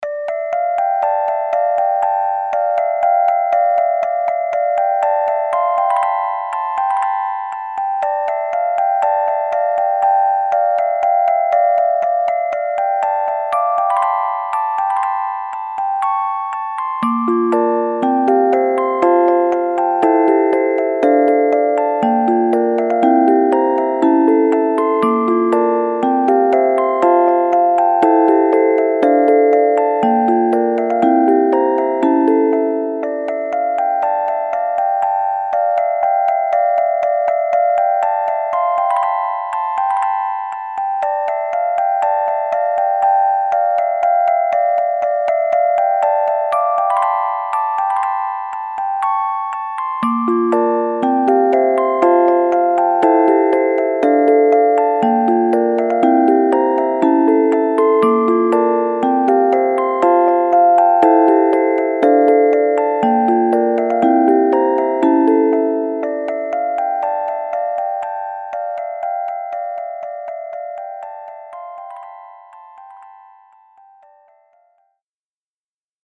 短く反芻するようなオルゴール曲です